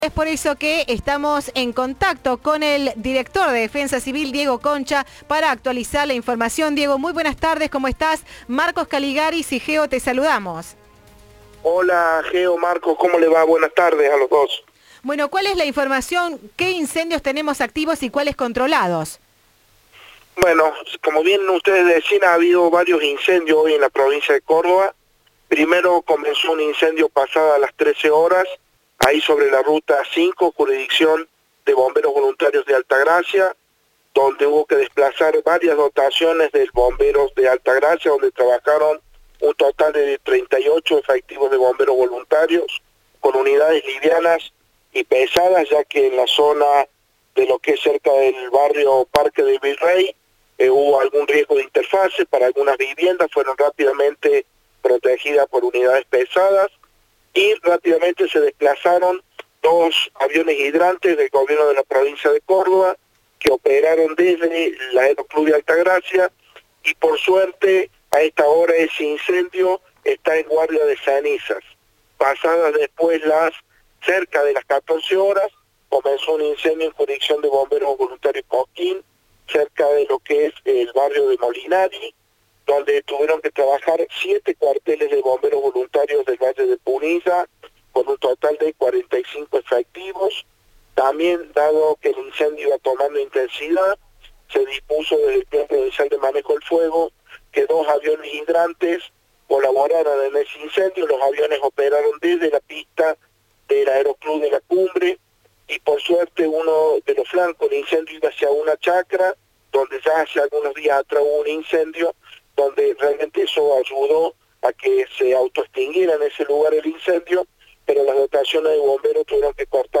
El director de Defensa Civil de Córdoba, Diego Concha, detalló a Cadena 3 cuáles fueron los focos de incendio este viernes en la provincia y advirtió que este finde semana el índice de peligrosidad será extremo.
Informe de Viva la Radio.